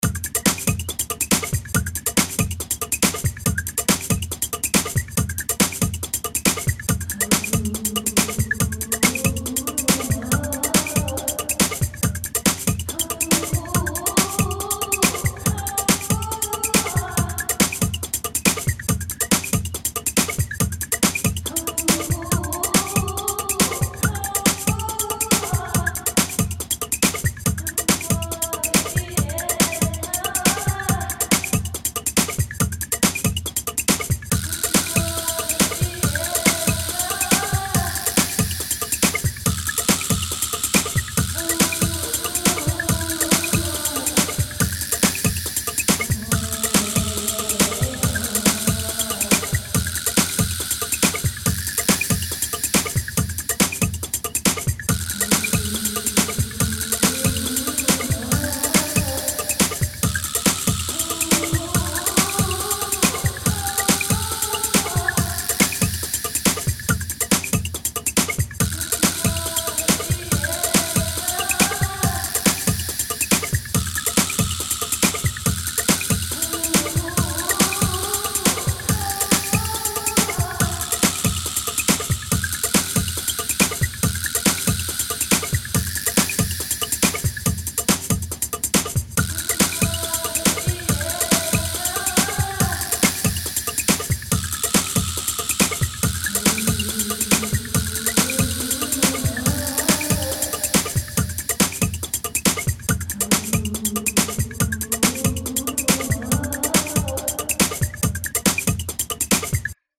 Music / Game Music
That tinkly sound that starts off and runs the underlying beat just sounds too zylophoney and Mario game to work for horror.